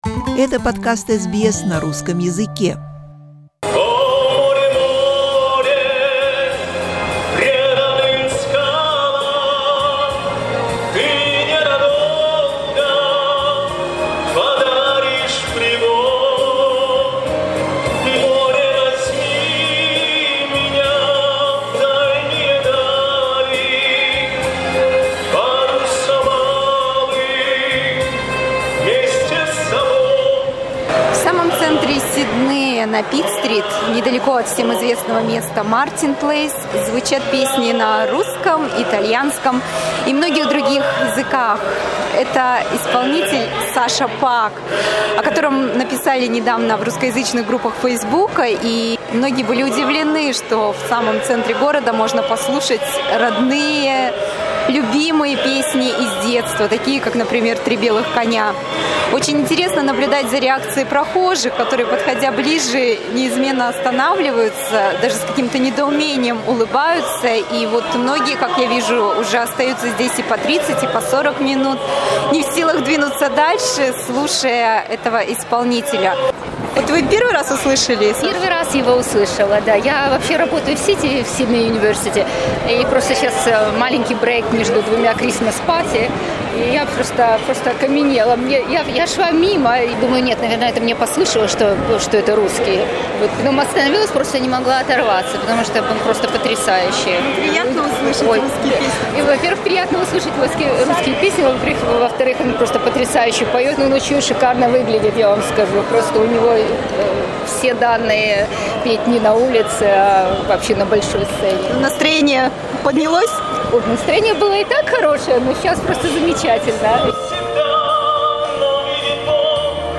Мы посетили одно из его уличных выступлений и пообщались с симпатичным музыкантом.